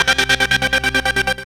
3504L SEQVOX.wav